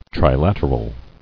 [tri·lat·er·al]